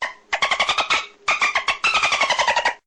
sounds_dolphin_chirp.ogg